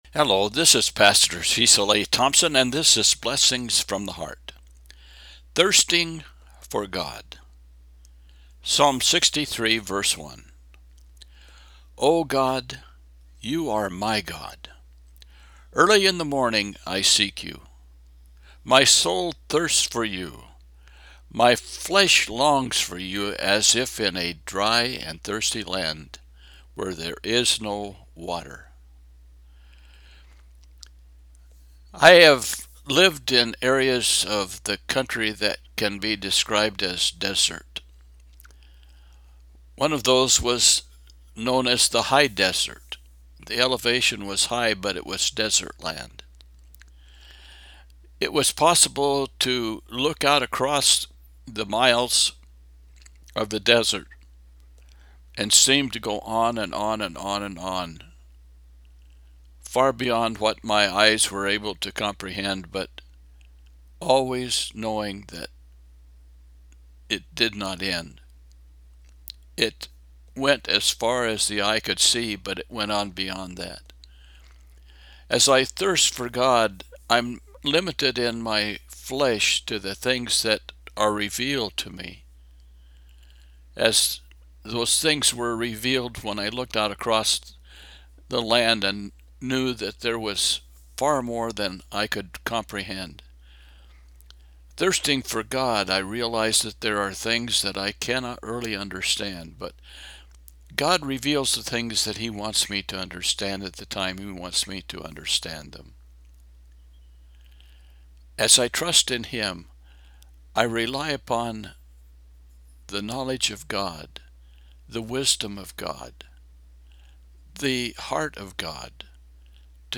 Psalm 63:1 – Devotional